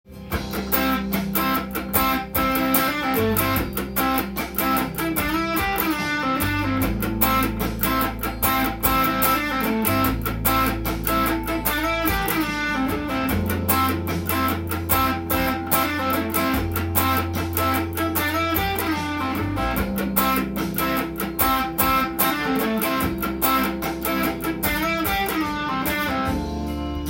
ファンキーなギターパート例
②は、keyがCになっているのでCの音を使いながら
ペンタトニックスケールをカッティングで入れていきます。
cutting.riff2_.m4a